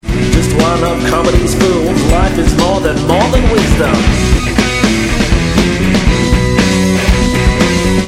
64k MP3 (8 secs, mono) ~